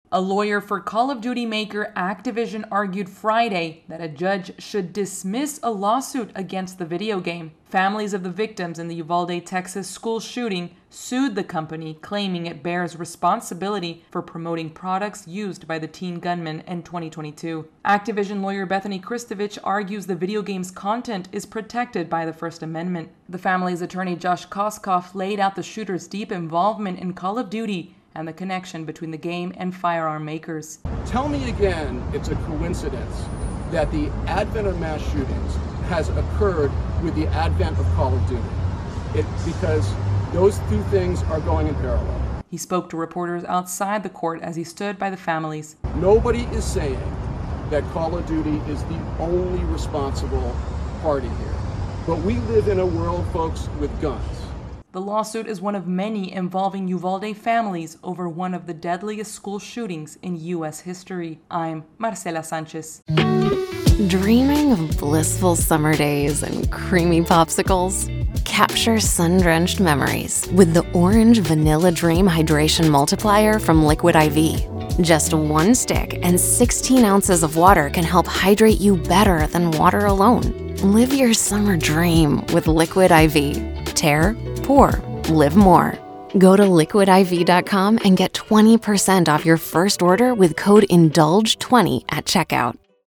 reports on the latest in a lawsuit against a video game, brought by families of victims in a Texas school shooting.